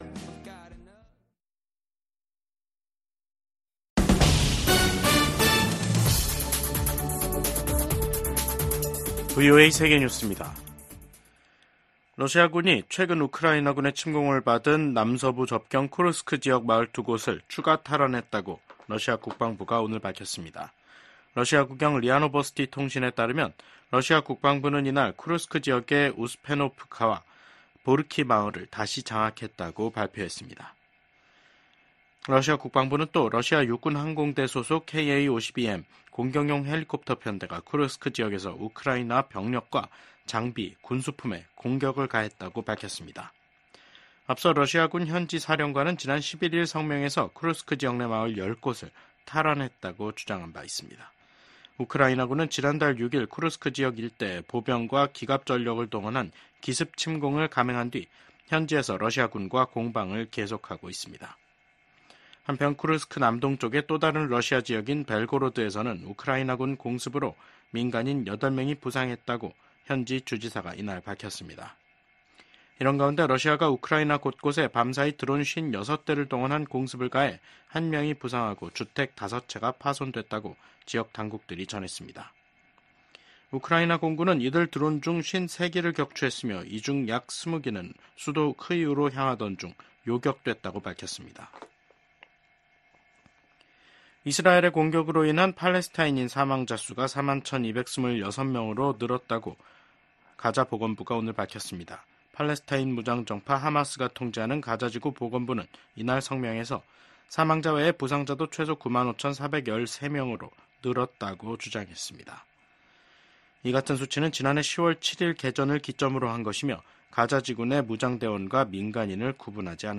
VOA 한국어 간판 뉴스 프로그램 '뉴스 투데이', 2024년 9월 16일 3부 방송입니다. 미국 백악관은 북한이 우라늄 농축시설을 공개한 것과 관련해 북한의 핵 야망을 계속 감시하고 있다고 밝혔습니다. 북한은 다음달 초 최고인민회의를 열고 헌법 개정을 논의한다고 밝혔습니다.